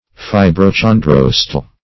Search Result for " fibrochondrosteal" : The Collaborative International Dictionary of English v.0.48: Fibrochondrosteal \Fi`bro*chon*dros"te*al\ (f[imac]`br[-o]*k[o^]n*dr[o^]s"t[-e]*al), a. [L. fibra a fiber + gr. cho`ndros cartilage + 'oste`on bone.]